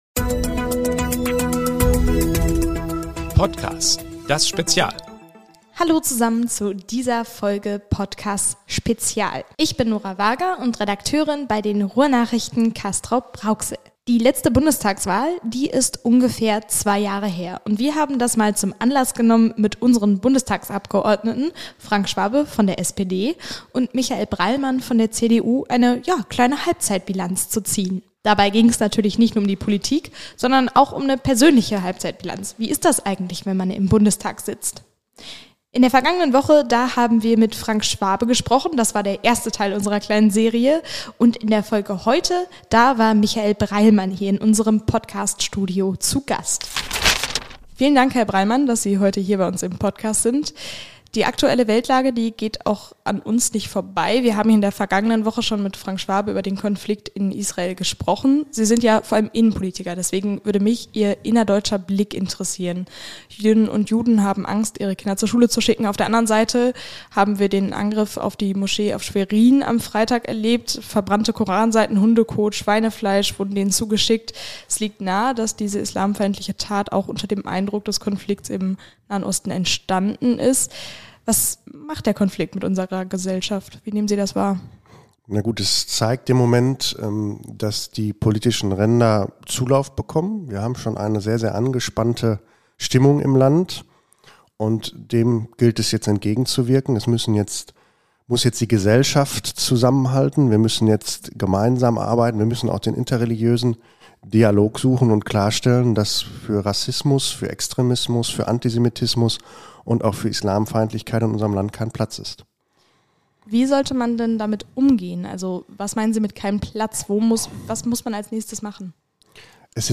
Mehr als die Hälfte der Legislaturperiode ist vorbei. Wir sprechen mit den Bundestagsabgeordneten Frank Schwabe (SPD) und Michael Breilmann (CDU) über ihre bisherige Bilanz in Berlin – politisch und persönlich.